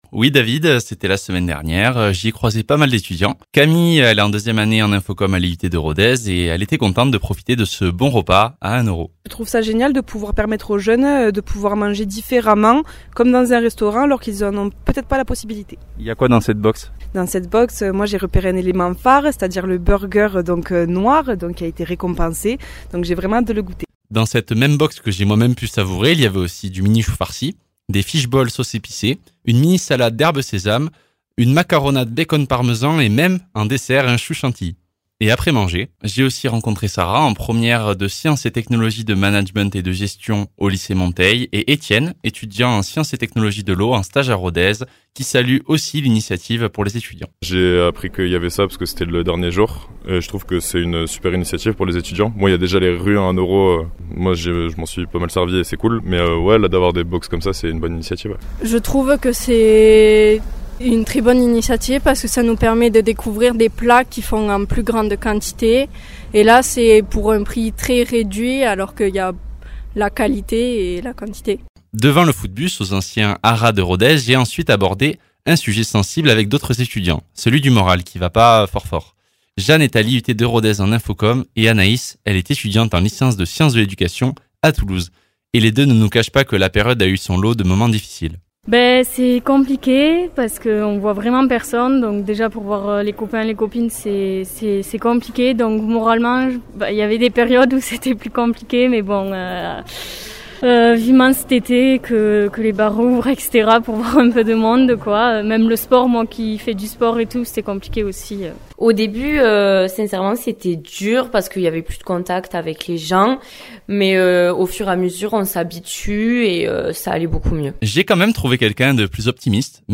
Un reportage auprès d’étudiants de Rodez à propos de la crise sanitaire et de la façon dont ils l’ont vécu
Interviews